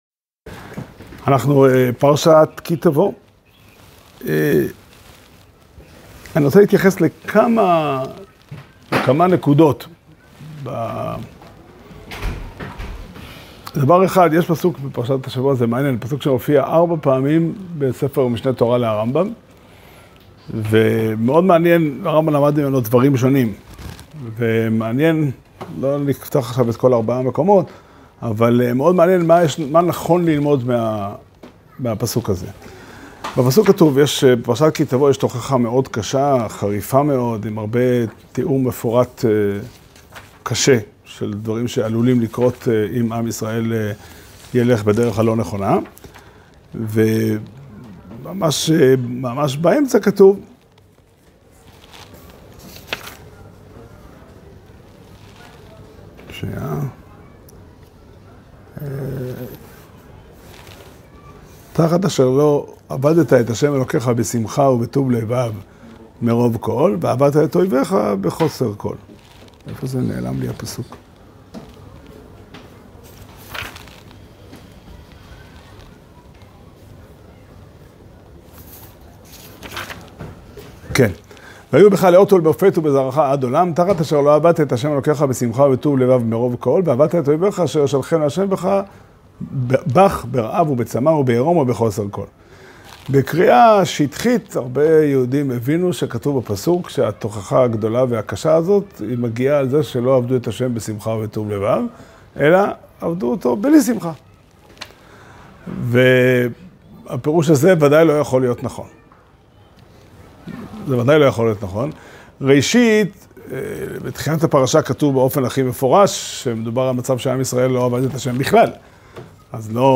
שיעור שנמסר בבית המדרש פתחי עולם בתאריך י"ג אלול תשפ"ד